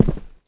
jumpland3.wav